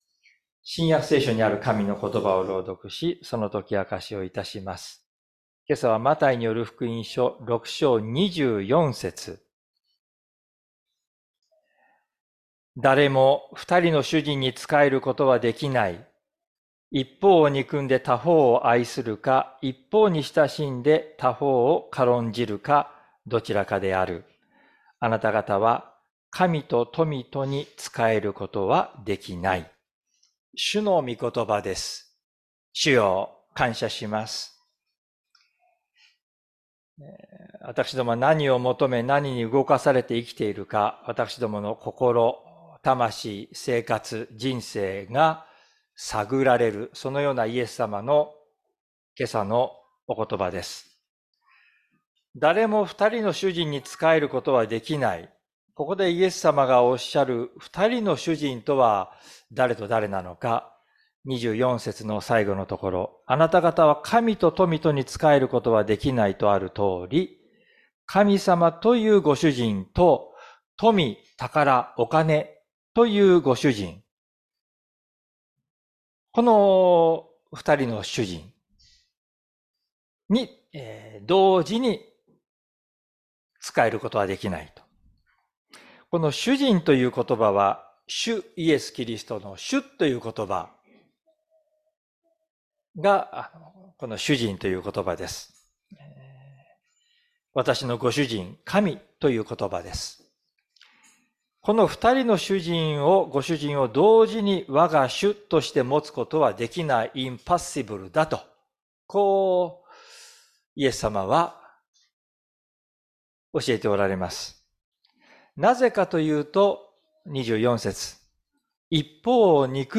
説教アーカイブ 日曜朝の礼拝 2024年02月25日「あなたの主人は誰？」
礼拝説教を録音した音声ファイルを公開しています。